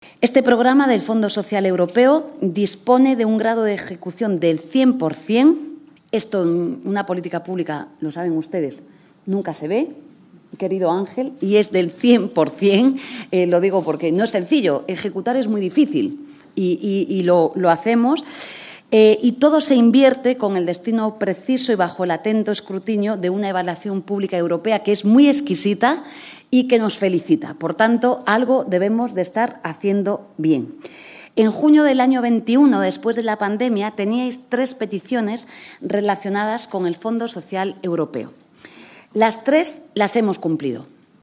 Esta es una de las principales conclusiones de la jornada ‘Más que empleo’, celebrada el 17 de mayo en el salón de actos del Ministerio de Trabajo y Economía Social, con presencia de la ministra de Trabajo y vicepresidenta del Gobierno, Yolanda Díaz, en la que se presentaron los resultados de las acciones desarrolladas en el periodo 2016-2023 así como los retos de futuro hasta 2027.